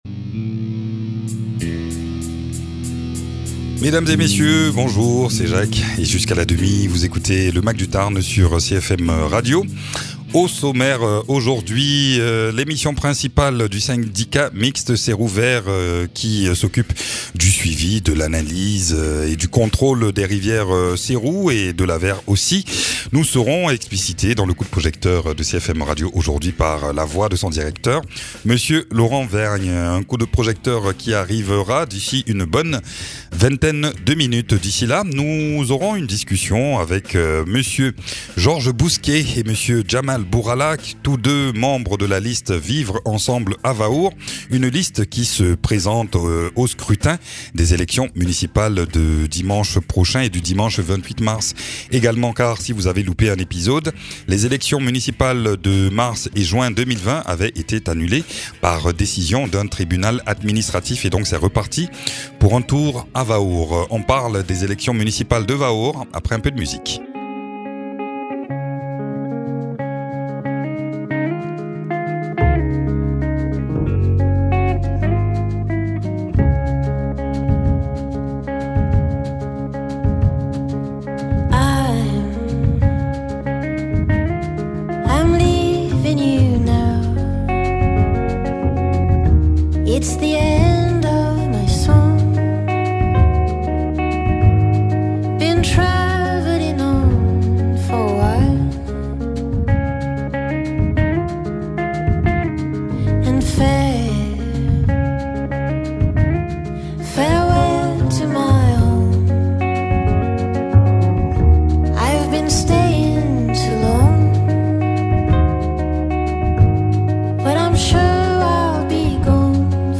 Aujourd’hui la liste "vivre ensemble à Vaour" vient débattre et discuter de leur profession de foi.